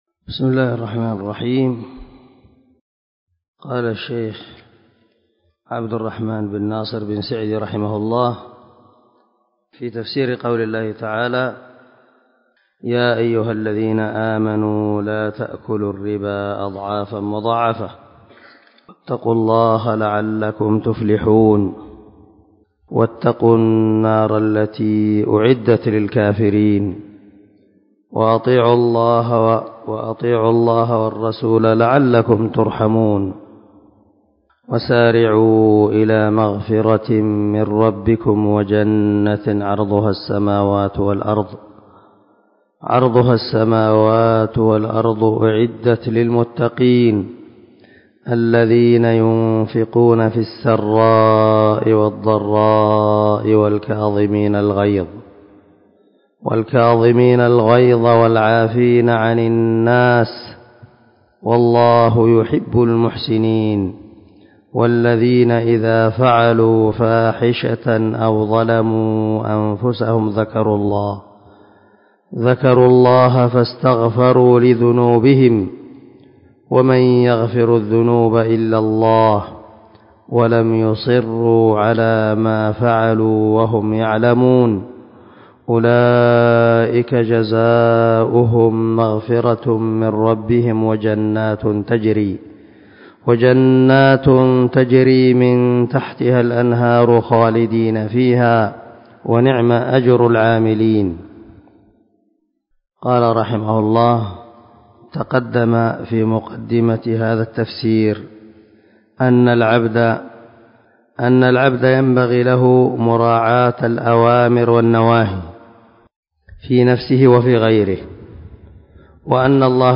199الدرس 44 تابع تفسير آية( 130 – 136 ) من سورة آل عمران من تفسير القران الكريم مع قراءة لتفسير السعدي
دار الحديث- المَحاوِلة- الصبيحة.